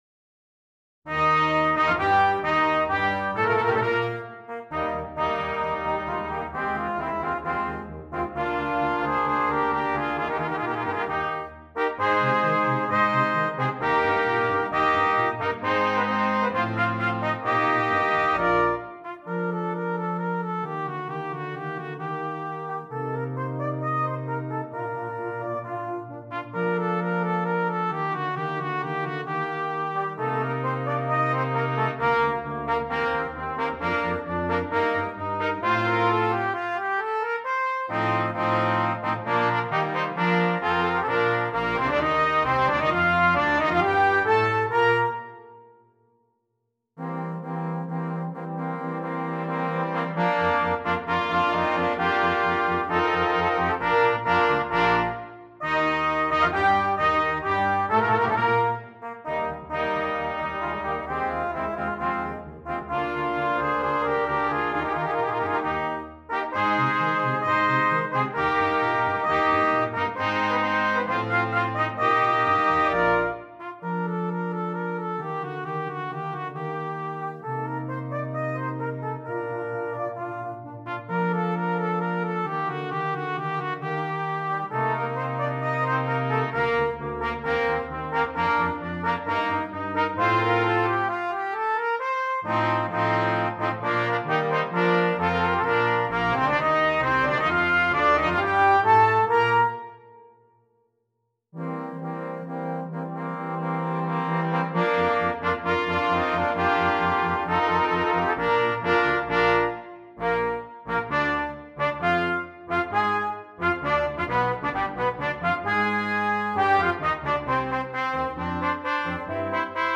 Brass Quintet
this piece works beautifully for brass quintet.